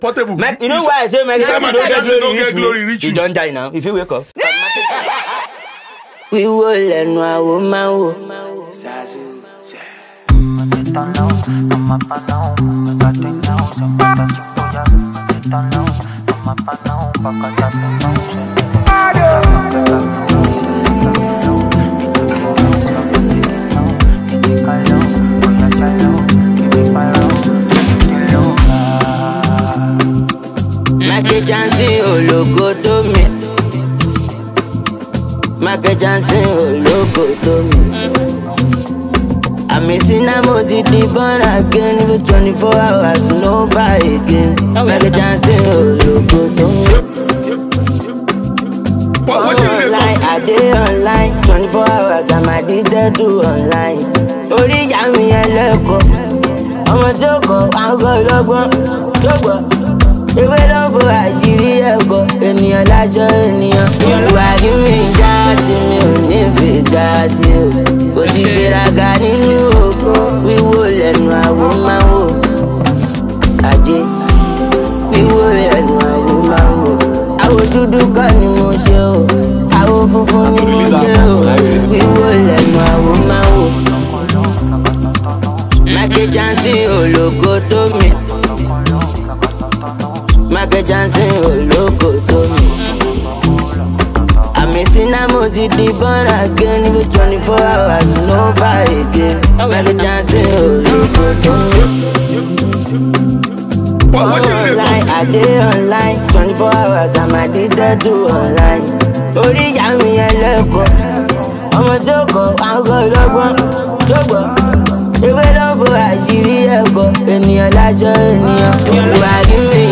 street-pop